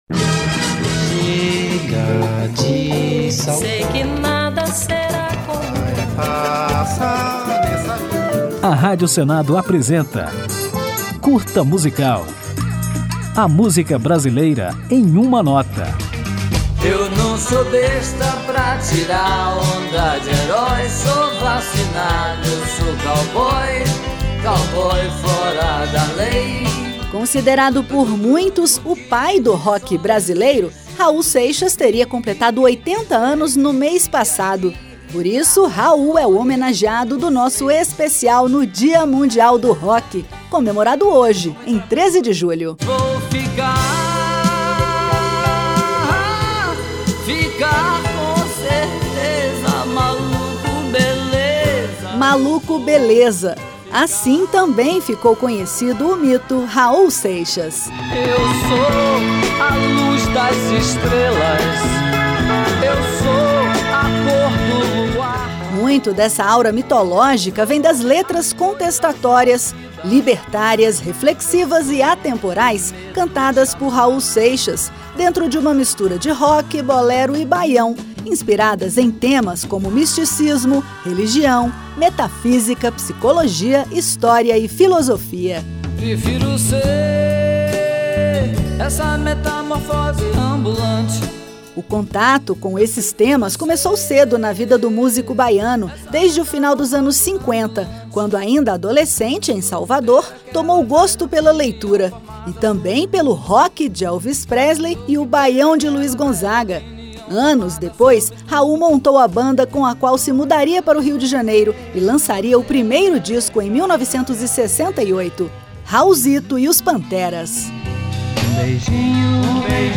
Depois de conhecer ou relembrar a trajetória do cantor e compositor baiano, vamos ouvi-lo no sucesso que rendeu a Raul Seixas um de seus mais célebres apelidos, a música Maluco Beleza.